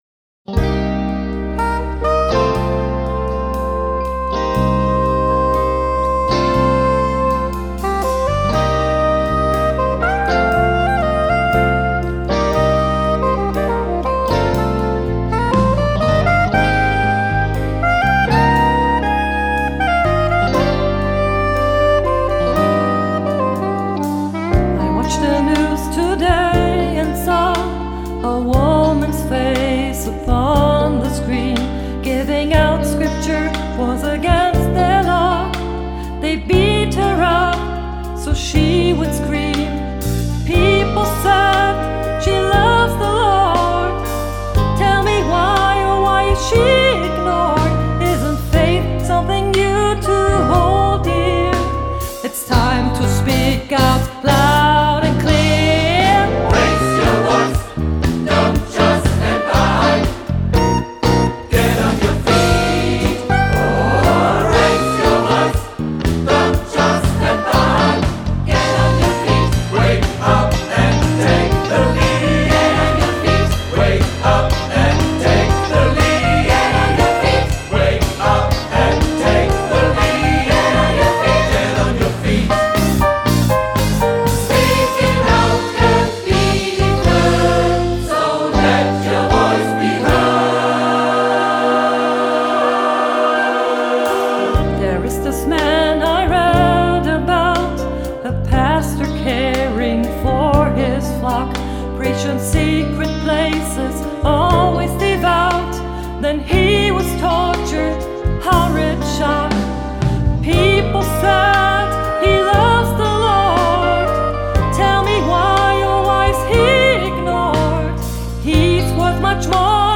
Gospejazz für Chor und Solo.
Gemischter Chor und Solist werden von der Band begleitet.
S.A.T.B., Klavier, Rhythmusgruppe ad lib.